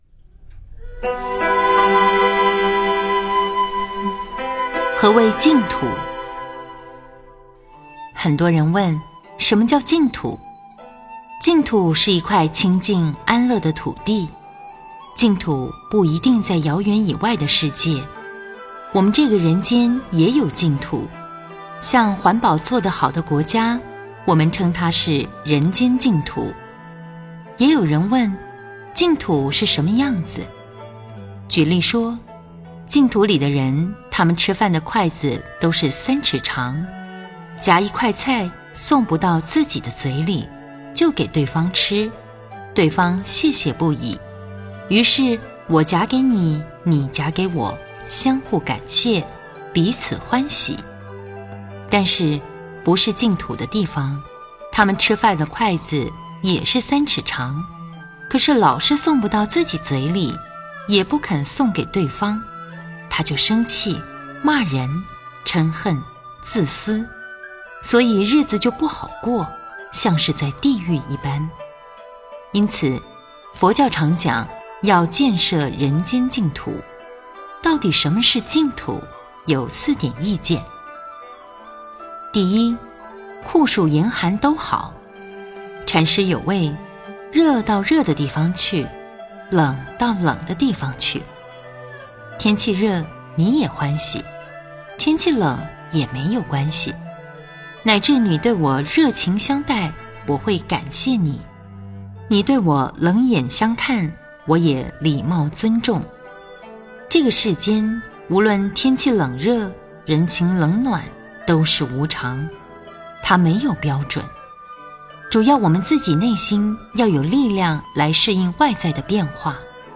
84.何谓净土--佚名 冥想 84.何谓净土--佚名 点我： 标签: 佛音 冥想 佛教音乐 返回列表 上一篇： 80.察言应对--佚名 下一篇： 85.菩萨的作为--佚名 相关文章 巴别塔《禅界》--世界禅风篇 巴别塔《禅界》--世界禅风篇...